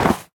Minecraft Version Minecraft Version latest Latest Release | Latest Snapshot latest / assets / minecraft / sounds / entity / snowman / hurt3.ogg Compare With Compare With Latest Release | Latest Snapshot
hurt3.ogg